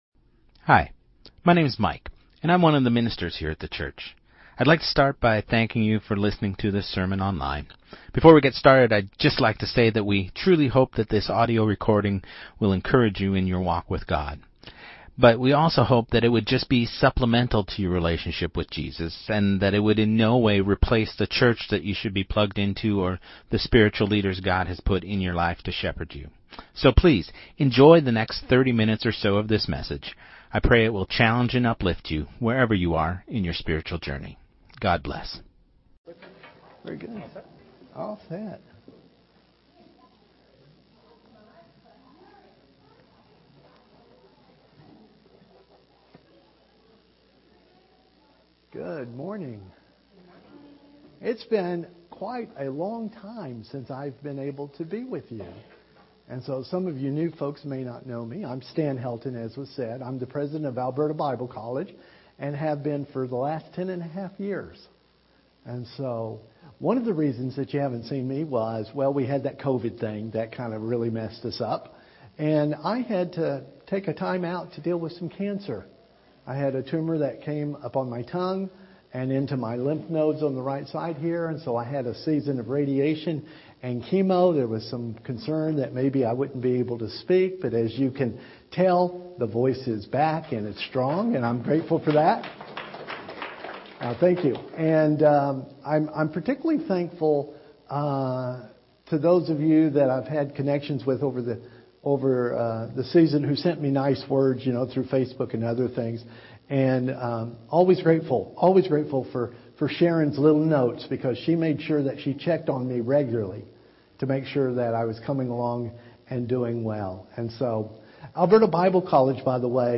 Sermon2026-02-22